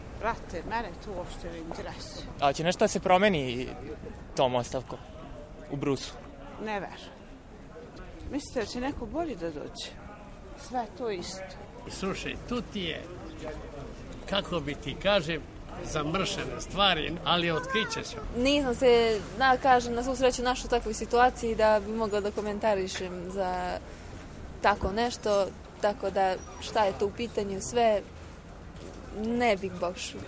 RSE je zabeležio razmišljanja nekih od građana Brusa.
Meštani Brusa o optužbama na račun Milutina Jeličića